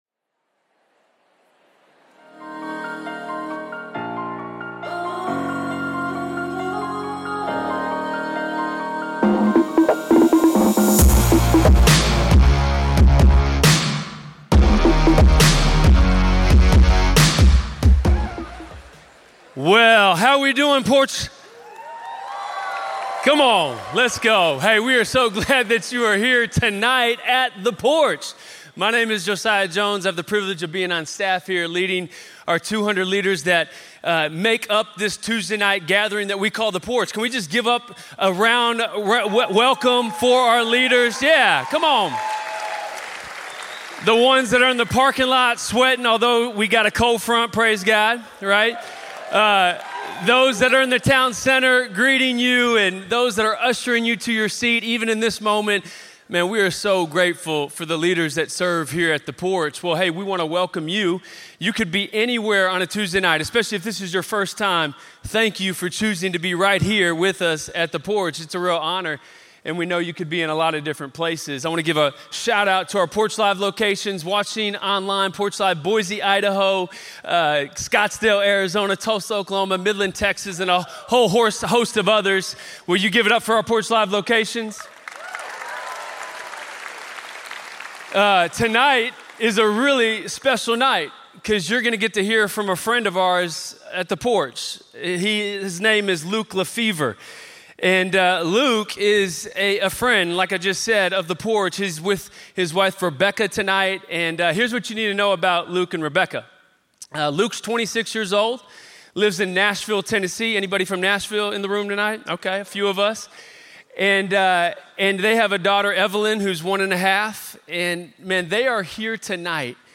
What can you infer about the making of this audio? Hey, we are so glad that you are here tonight at the porch.